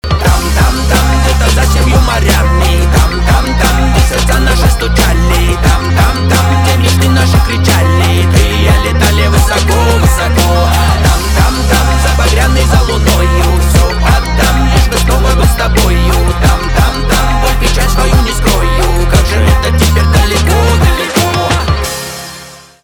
поп
чувственные
грустные , битовые , басы